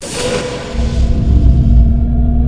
doormove6.wav